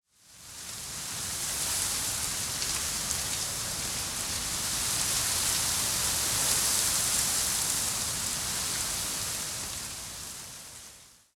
windtree_11.ogg